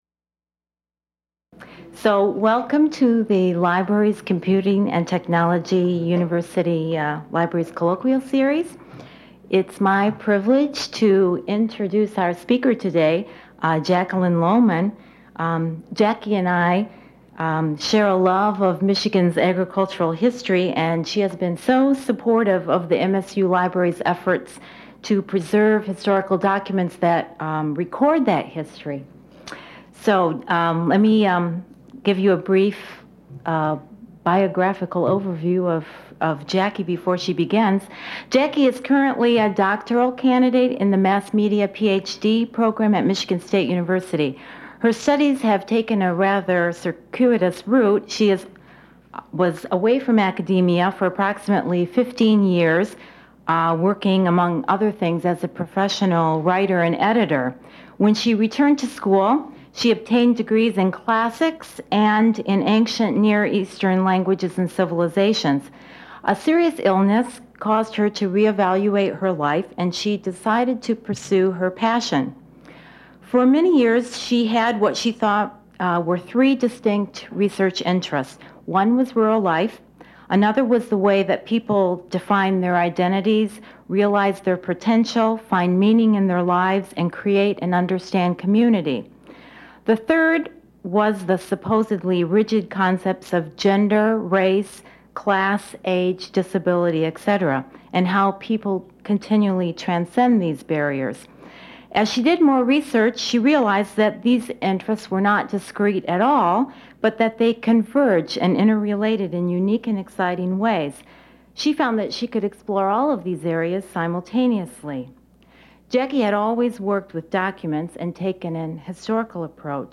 She answers questions from the audience.
Part of the Michigan State Libraries' Colloquia Series. Held in the Main Library.